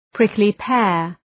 Προφορά
prickly-pear.mp3